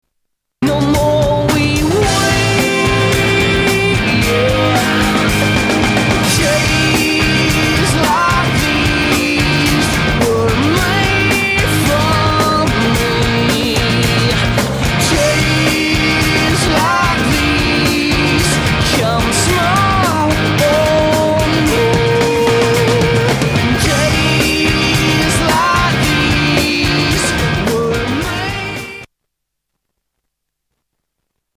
British pop-rockers
modern rock band